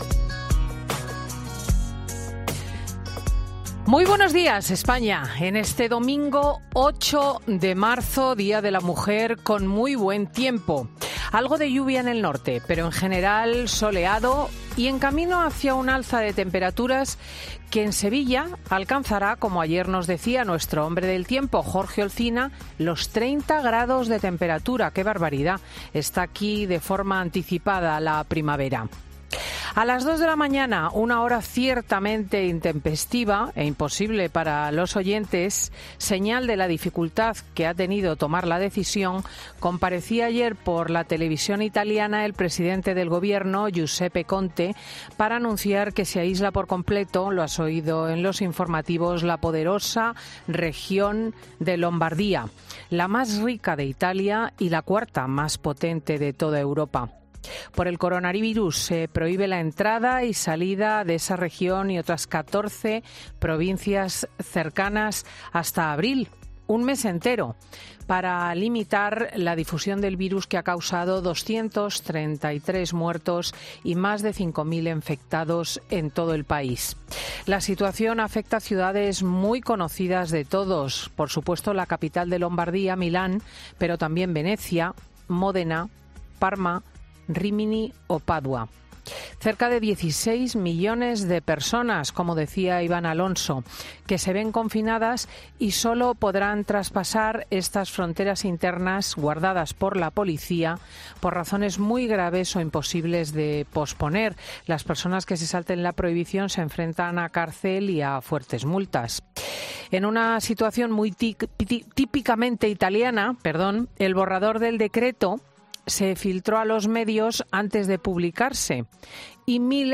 Así comienza el monólogo de este domingo, Cristina López Schlichting, una editorial dedicada en gran medida a la crisis del coronavirus y con especial subrayado al 8-M, Día Internacional de la Mujer y las manifestaciones convocadas en toda España.